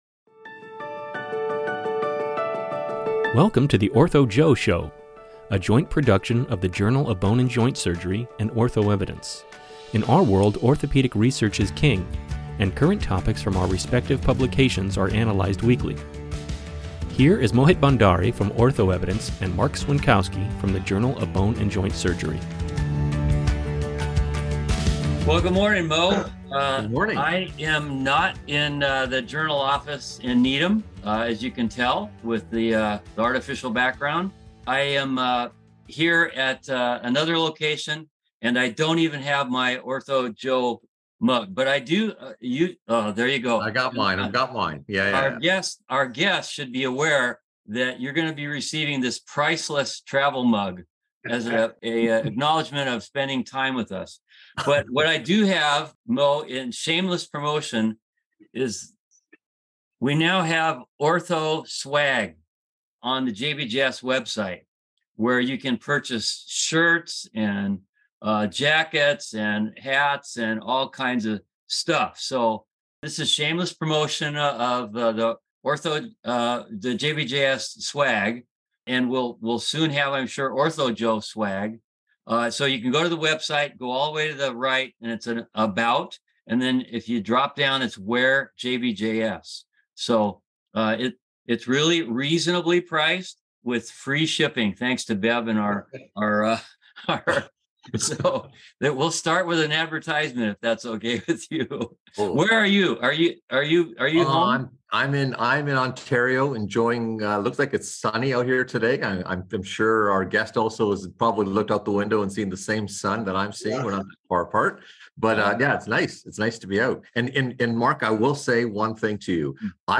in a wide-ranging discussion of the past, present, and future of arthroplasty, tumor surgery, and fresh allograft transplantation.